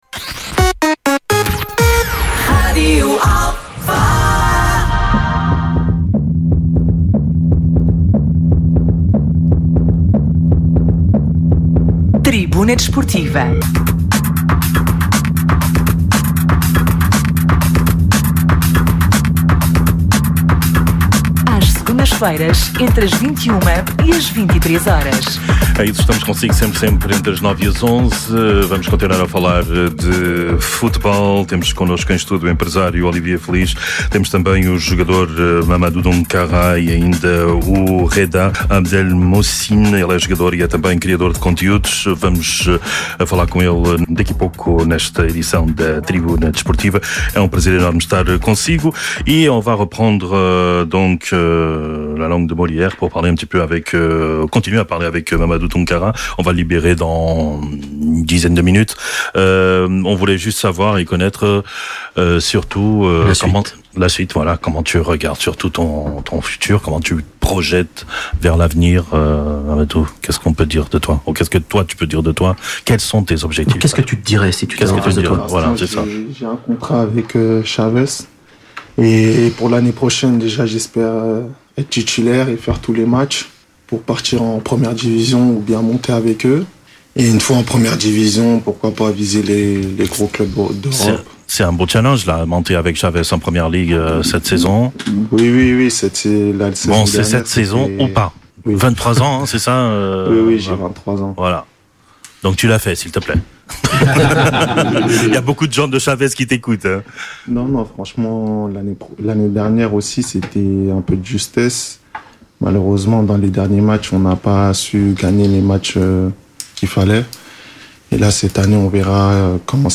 Tribuna Desportiva é um programa desportivo da Rádio Alfa às Segundas-feiras, entre as 21h e as 23h.
Atualidade Desportiva, Entrevistas, Comentários, Crónicas e Reportagens.